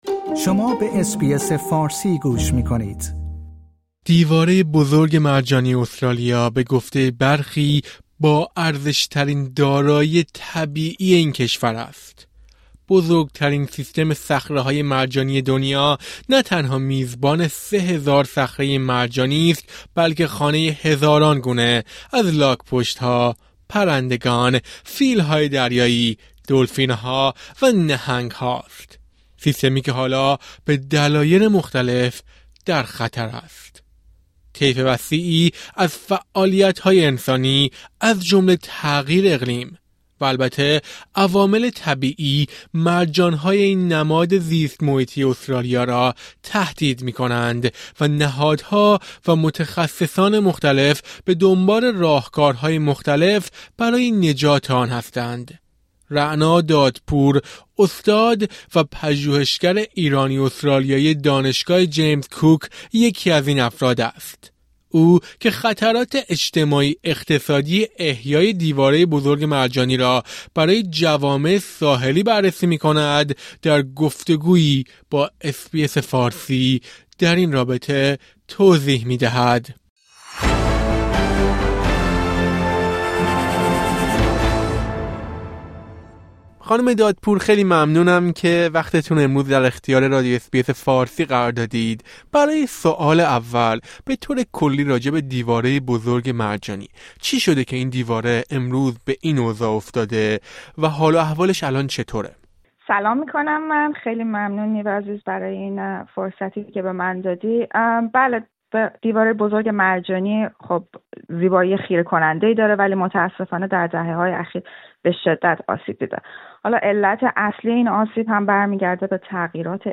هوش مصنوعی شاید به تلاش‌ها برای نجات دیواره بزرگ مرجانی استرالیا کمک کند. یک استاد دانشگاه ایرانی که مسئول بخشی از تحقیقات درباره نجات دیواره است، در این رابطه توضیح می‌دهد.